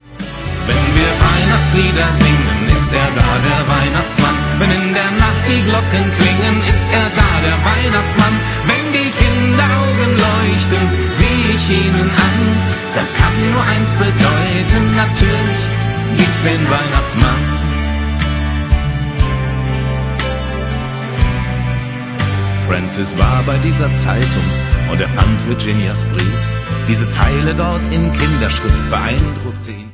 Advents- und Weihnachtsleedcher in Kölner Mundart